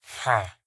sounds / mob / villager / no3.ogg